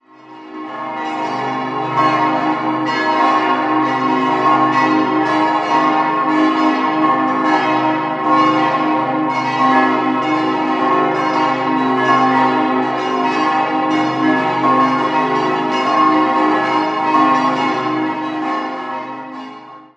Idealsextett: c'-es'-f'-as'-b'-c'' Alle Glocken wurden 1958 von der Gießerei Petit&Edelbrock in Gescher (Westfalen) gegossen.